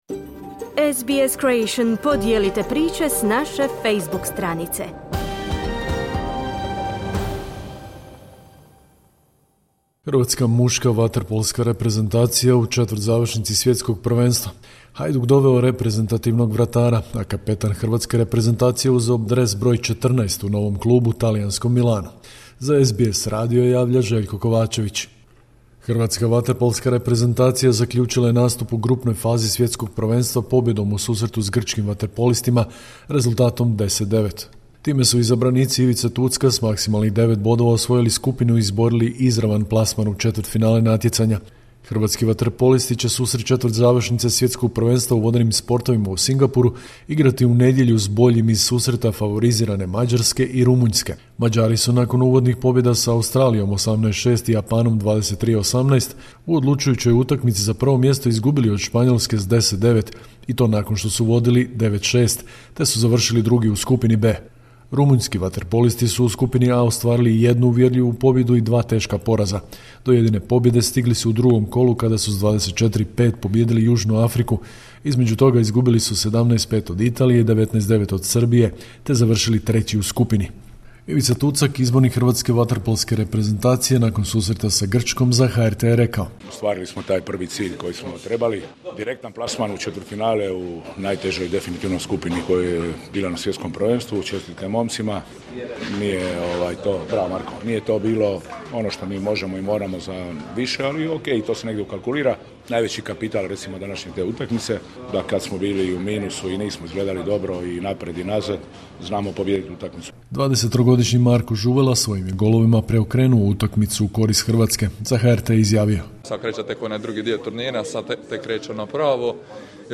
Sportske vijesti, 17.7.2025. Hrvatska muška vaterpolska reprezentacija u četvrtzavršnici svjetskog prvenstva. Hajduk doveo reprezentativnog vratara, a kapetan hrvatske reprezentacije Luka Modrić uzeo dres broj 14 u novom klubu, talijanskom Milanu